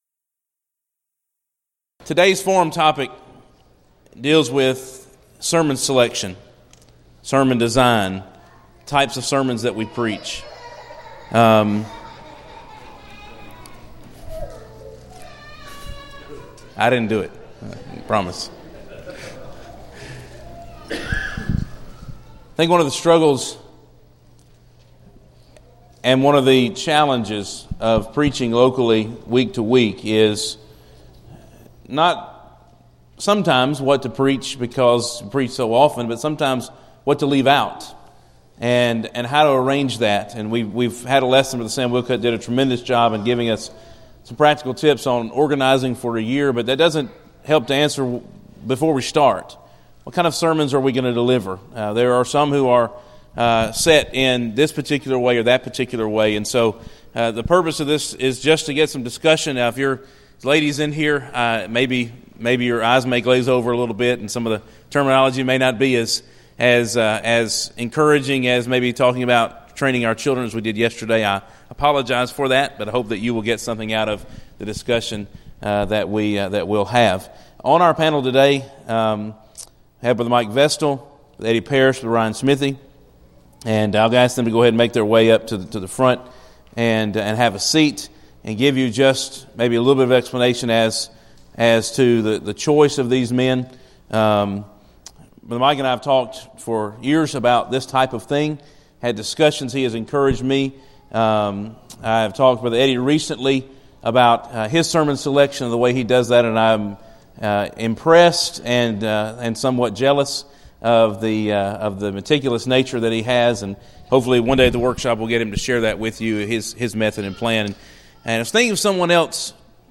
Event: 2014 Focal Point Theme/Title: Preacher's Workshop